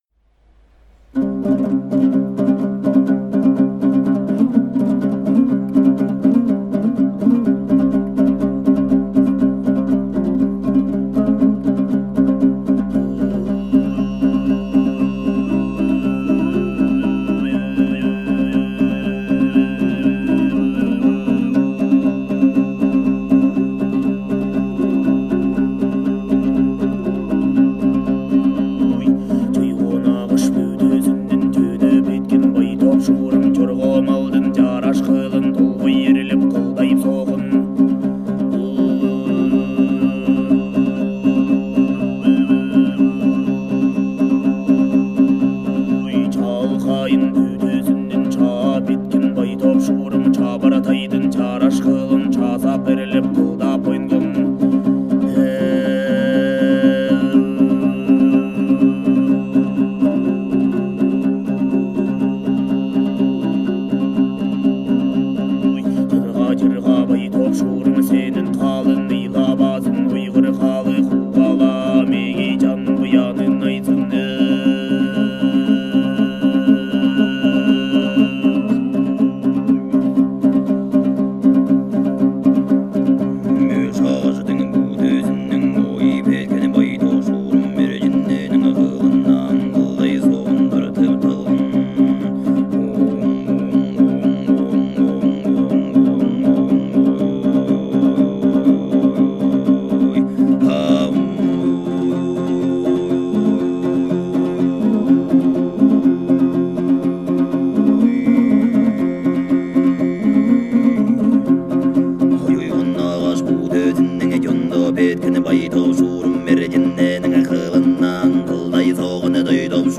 Altai-Throat-Singing498.mp3